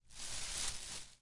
leavesrustle
描述：the sound as if you were walking through leaves pushing them with your hands and legs as you walk through their intended space
标签： moving through leaves rustling rustle leaf
声道立体声